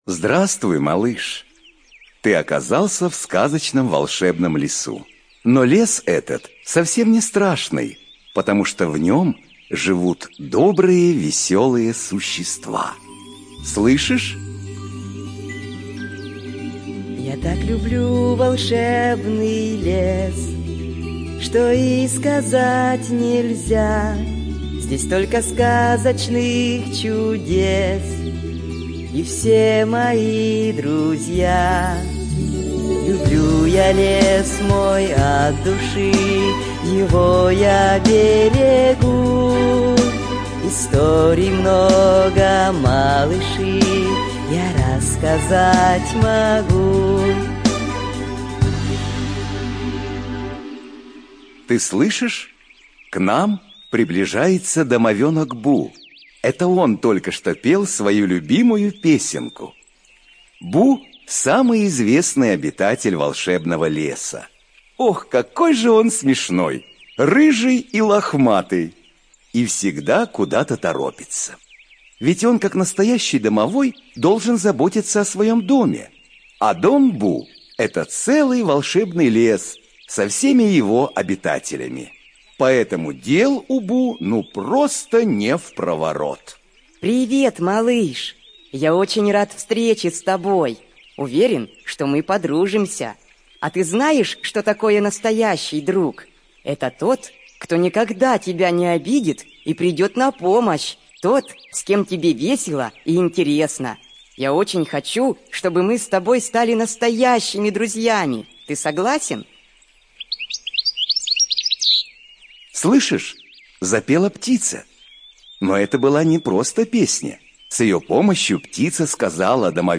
ЖанрДетская литература, Наука и образование
Студия звукозаписиИДДК